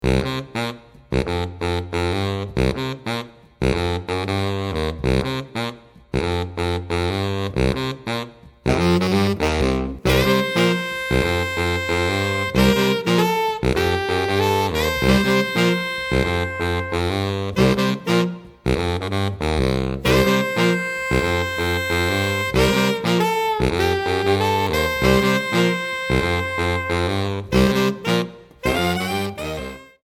4 Saxophones (SATB)